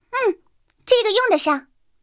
三号获得资源语音.OGG